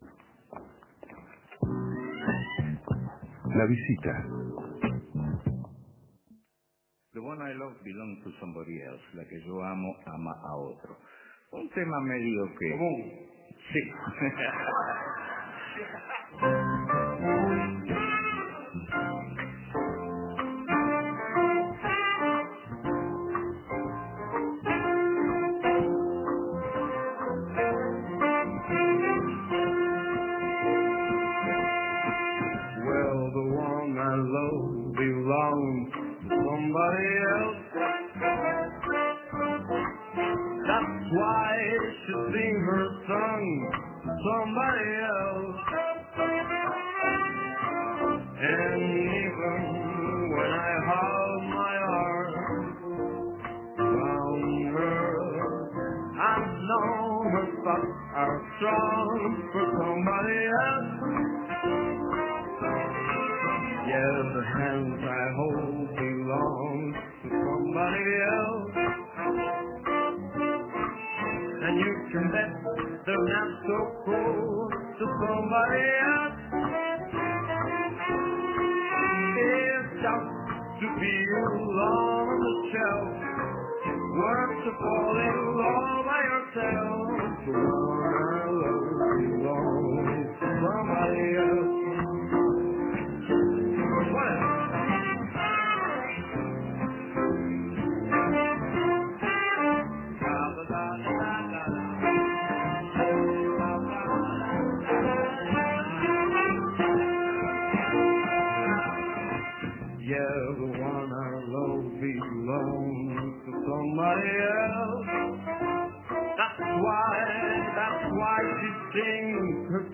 Escuche la entrevista y la actuación en vivo de Memphis Jazz Band en los estudios de la radio.